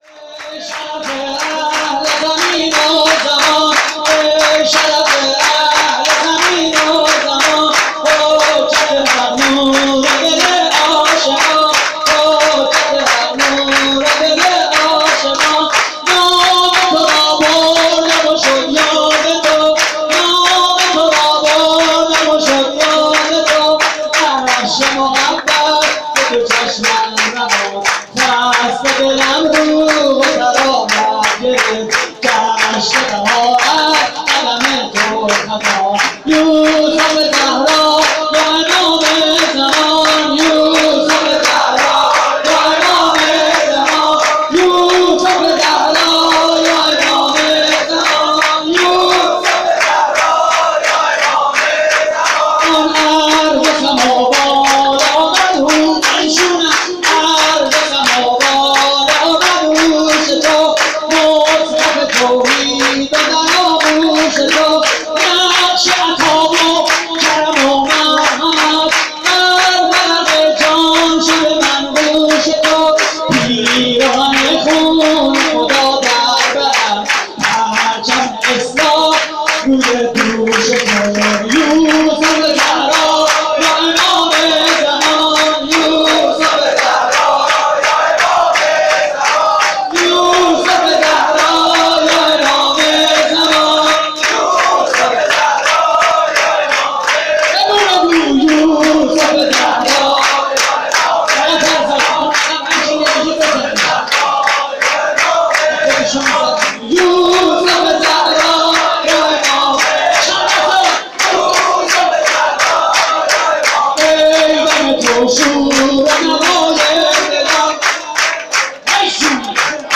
جشن ولادت امام زمان (عج) / هیئت کانون امام علی (ع) - عبدل آباد؛ 12 خرداد 94
صوت مراسم:
شور: ای شرف اهل زمین و زمان؛ پخش آنلاین |